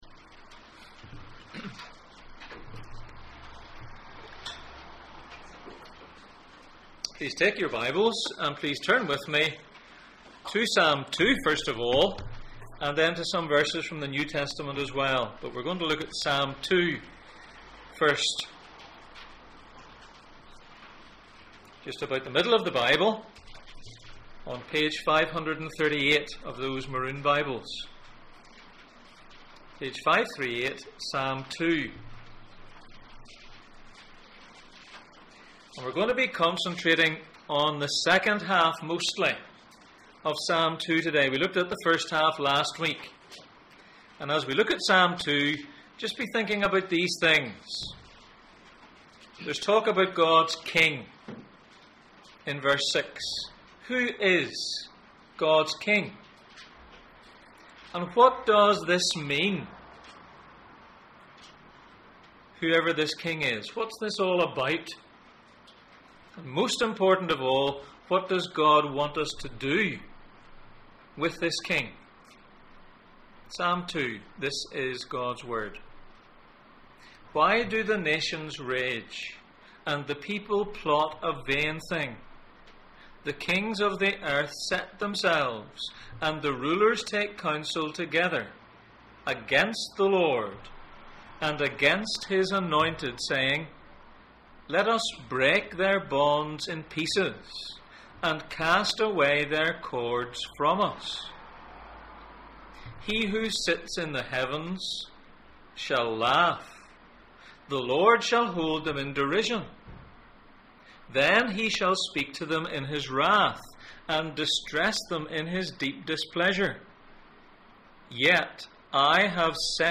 Psalms Passage: Psalm 2:1-12, Luke 9:28-36, Hebrews 1:5-7, Mark 1:11, Acts 13:30-33, Hebrews 5:5 Service Type: Sunday Morning